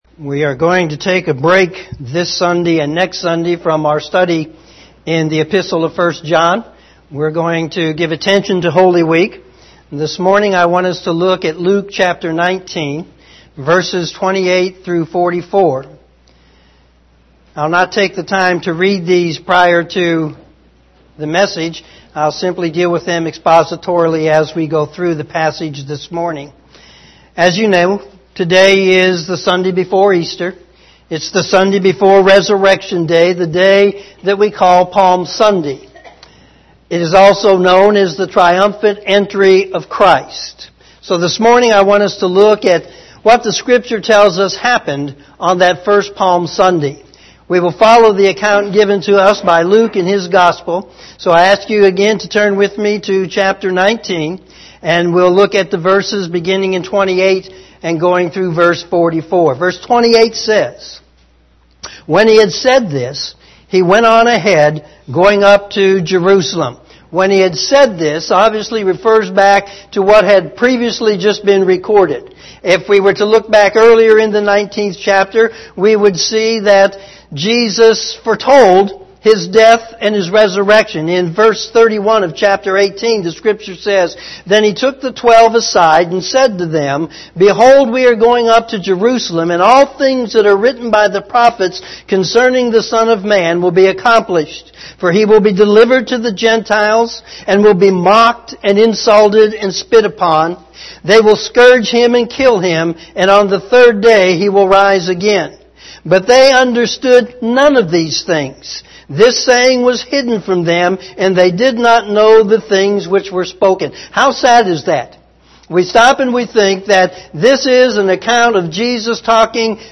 Morning Sermon
sermon4-25-18am.mp3